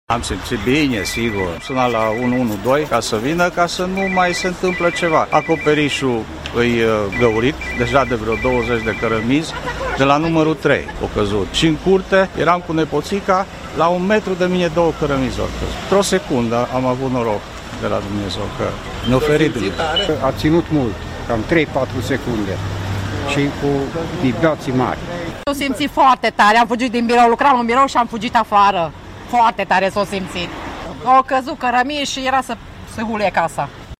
01.-voxuri-arad-2.mp3